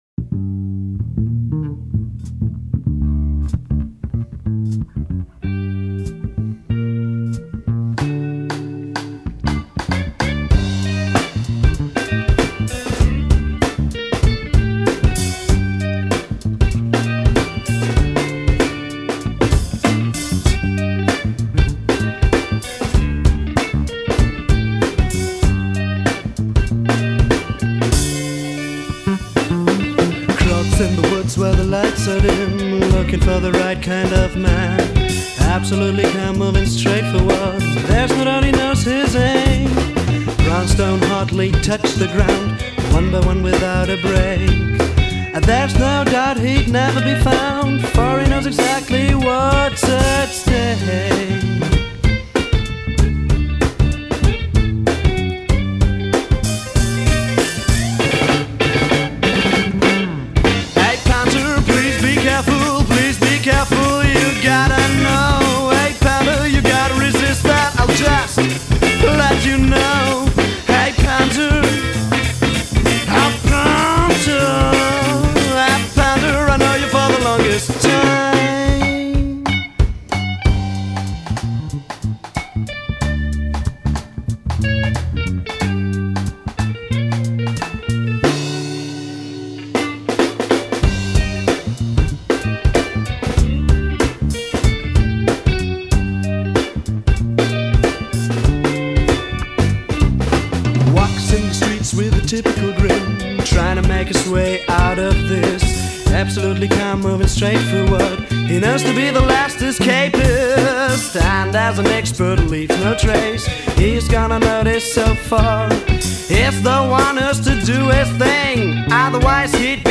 im Wki-Studio in Bad Münstereifel
Trompete
Gesang